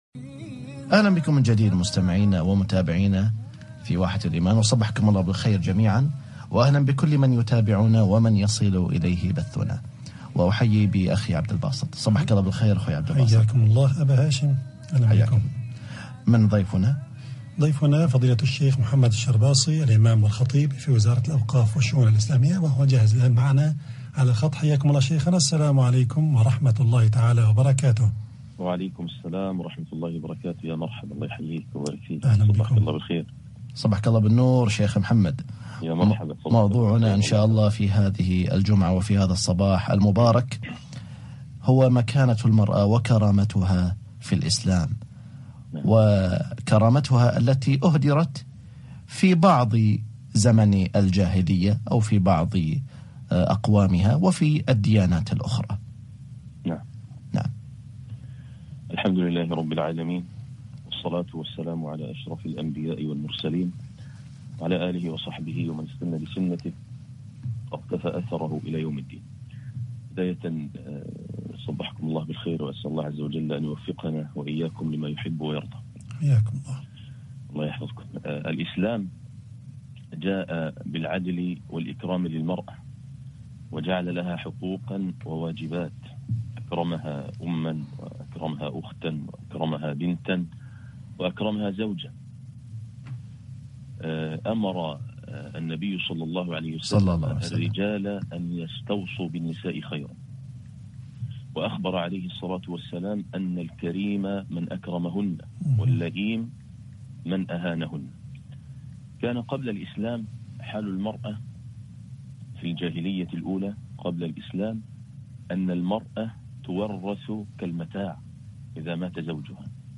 حقوق الزوجة في الإسلام ومكانة المرأة - حلقة إذاعية